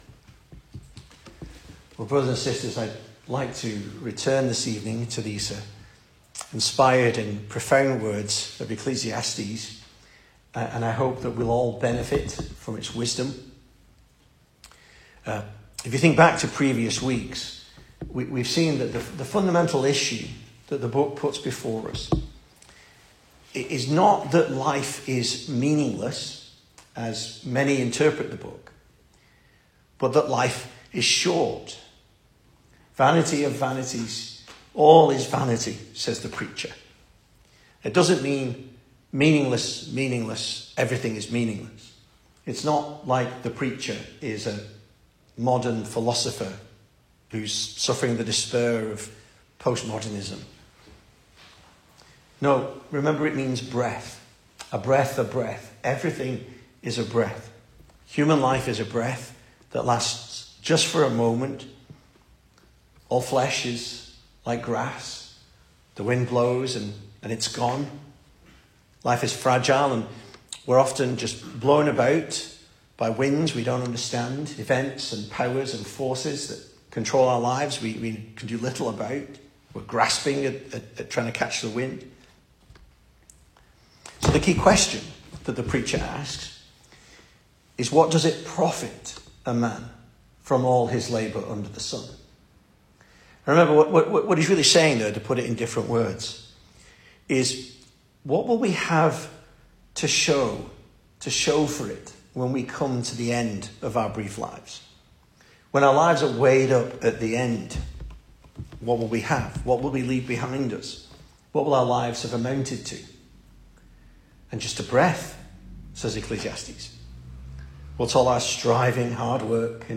2025 Service Type: Weekday Evening Speaker